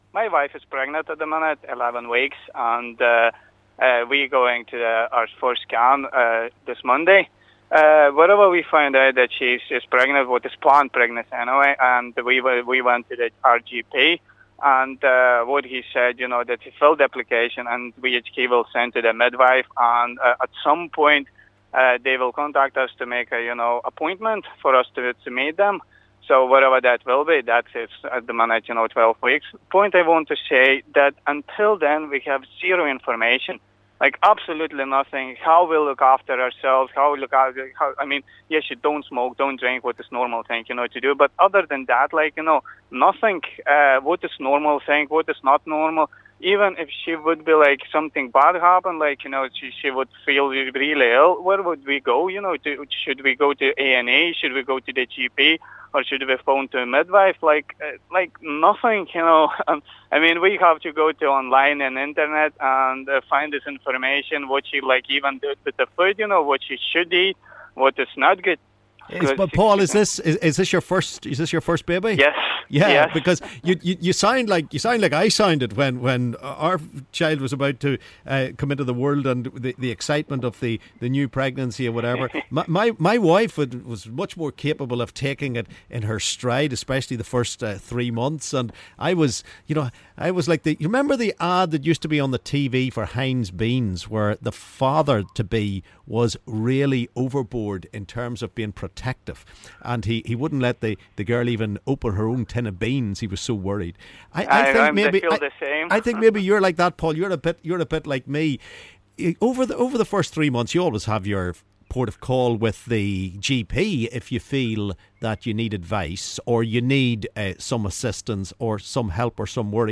LISTEN: Caller angry at lack of pre-natal care